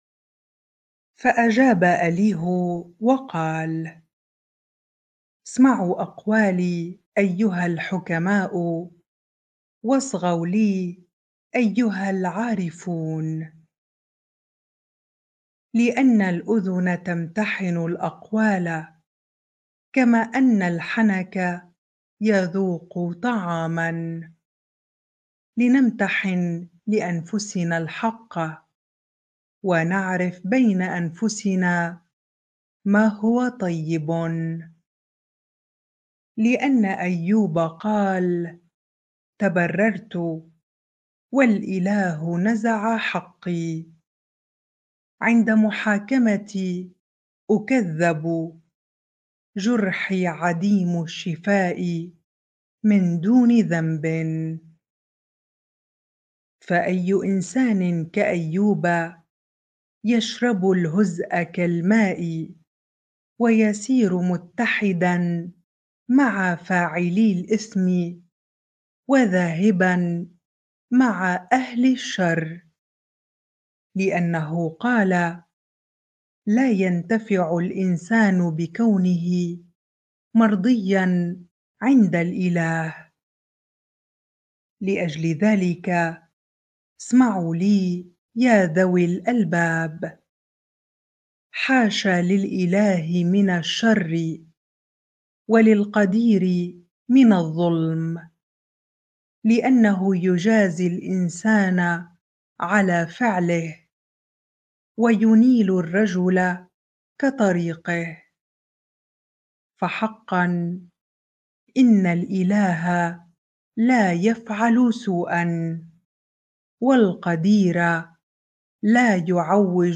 bible-reading-Job 34 ar